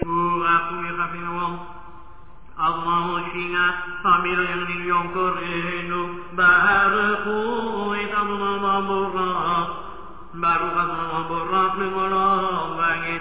Chazzan